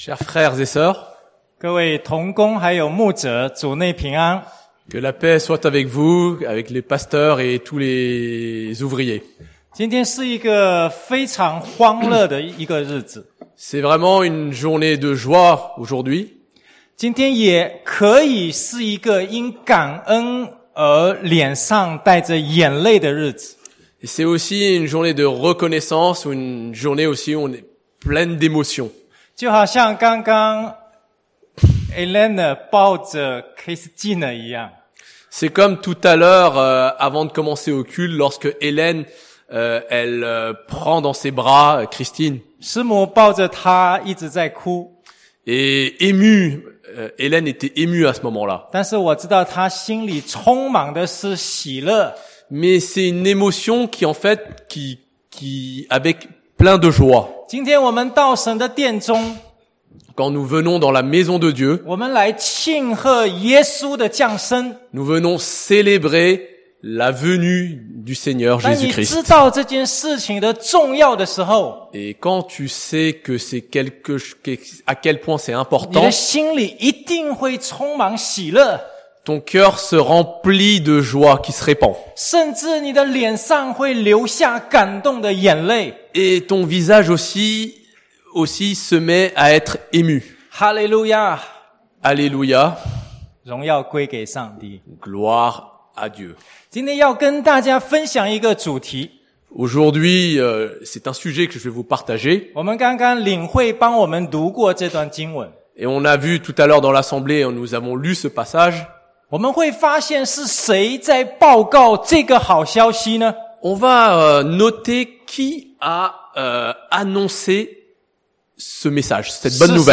chinois traduit en français